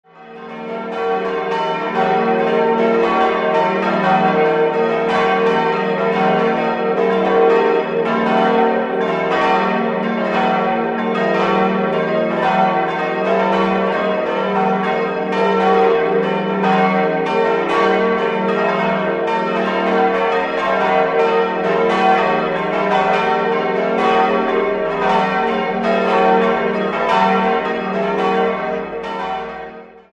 5-stimmiges Geläute: e'-fis'-a'-c''-dis''
bell